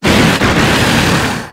892_rapid-strike.wav